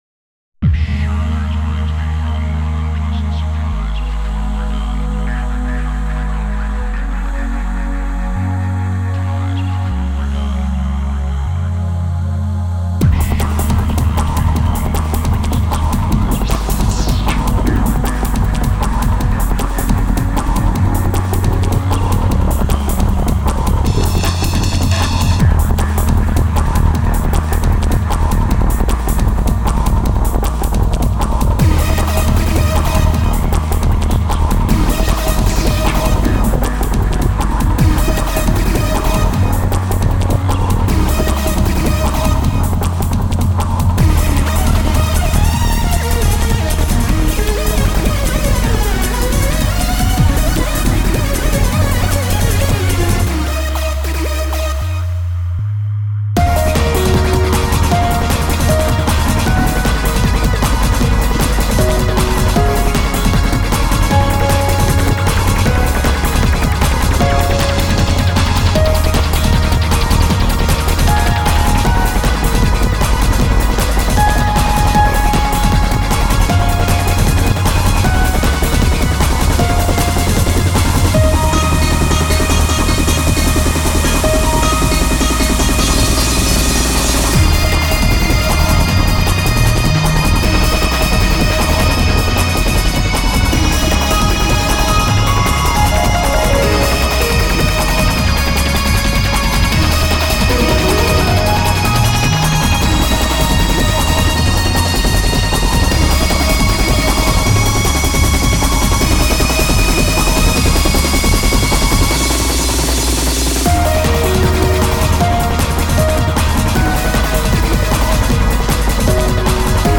原曲のキーフレーズを取り込みつつ、スピード感のあるD'n'Bにしたかったので、そこが苦労点でした。